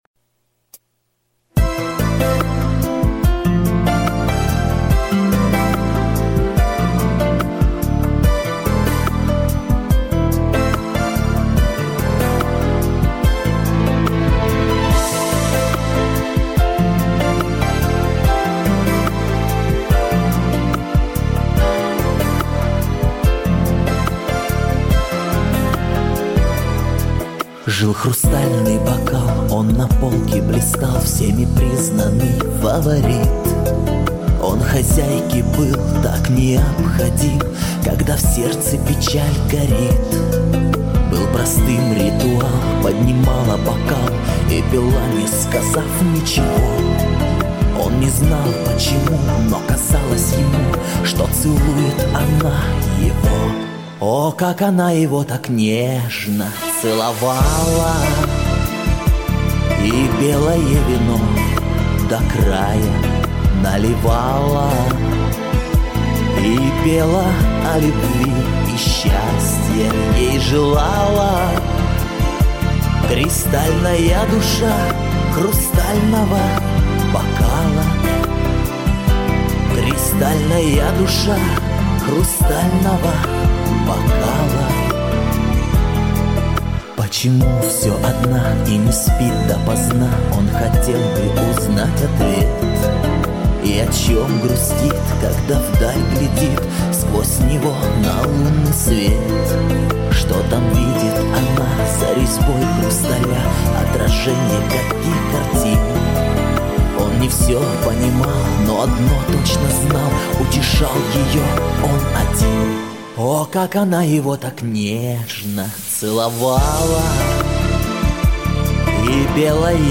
Певческий голос
Баритон Тенор